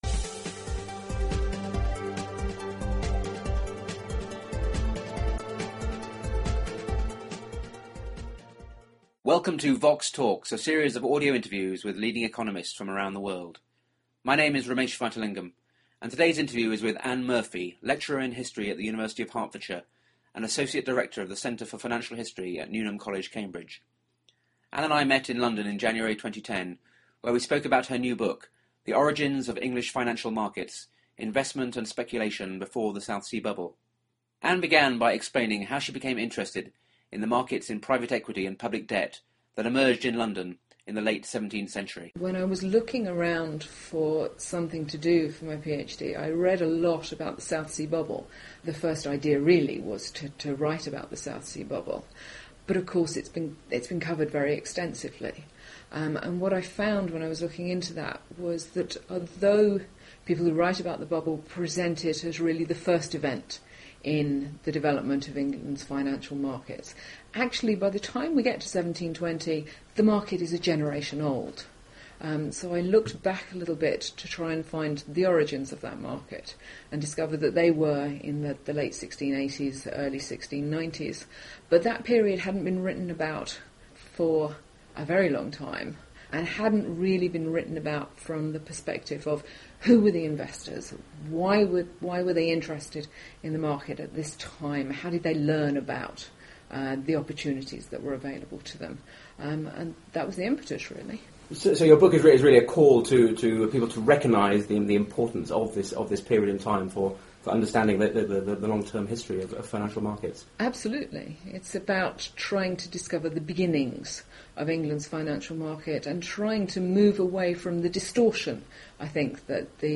The interview was recorded in London in January 2010.